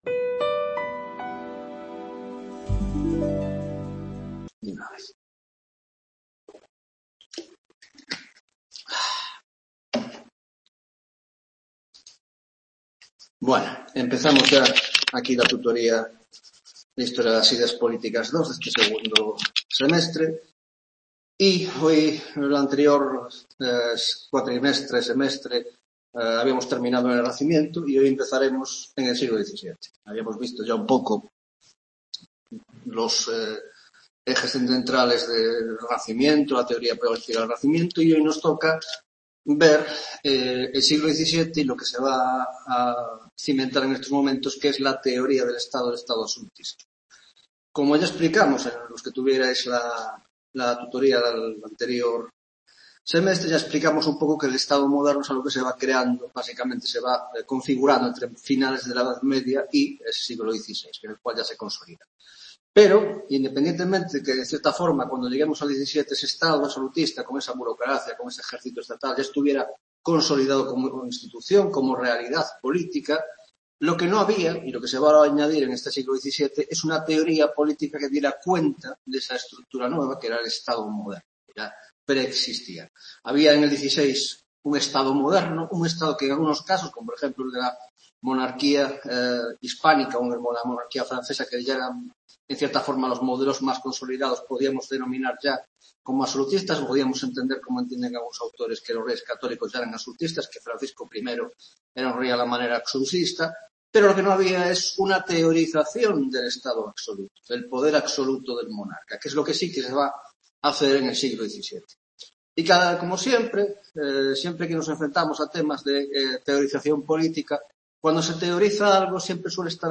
1ª Tutoria de Historia de las Ideas Políticas II (Grado de Ciéncias Políticas ) - Teoría Política del Absolutismo (1ª parte): 1) Introducción: caracteres generales del siglo XVII y el Absolutismo; 2) Los teóricos del Absolutismo: Jean Bodin y Hobbes contexto histórico y obras principales; 3) El Leviatan y la Teoría política de Thomas Hobbes